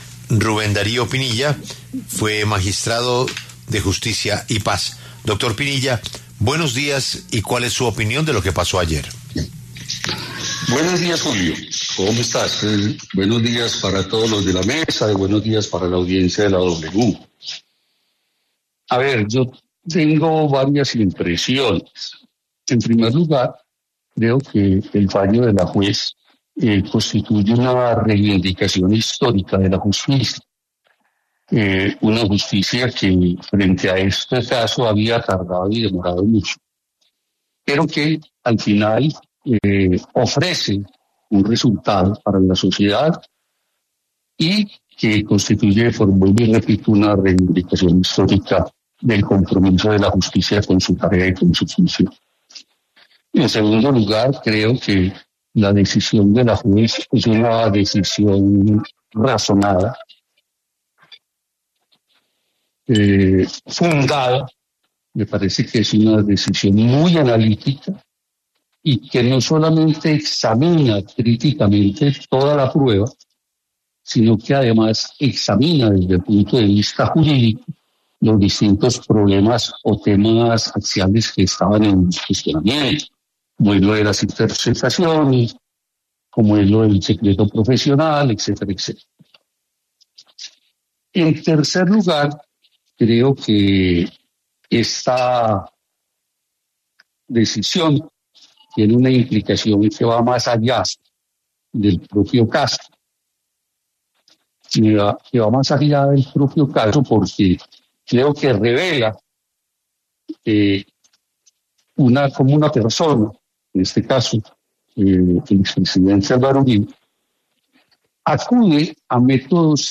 El exmagistrado de la Sala de Justicia y Paz, Rubén Darío Pinilla, pasó por los micrófonos de La W para referirse al tema, destacando algunos puntos clave.